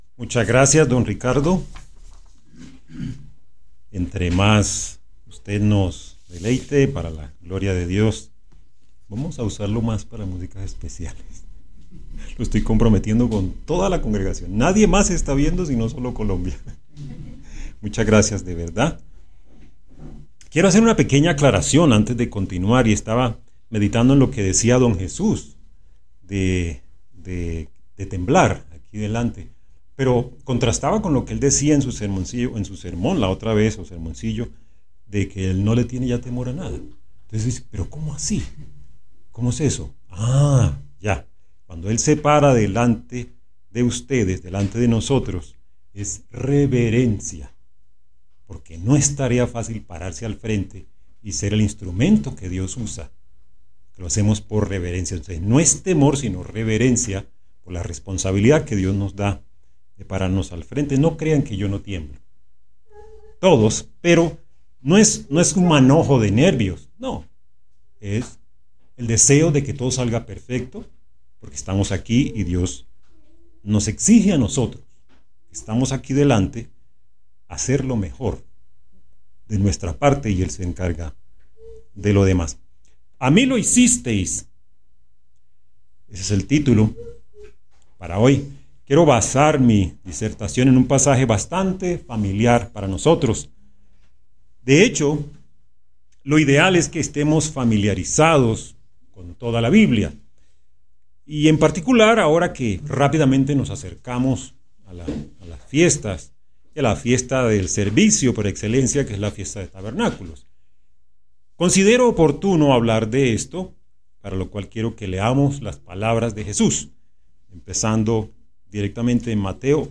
Given in Cúcuta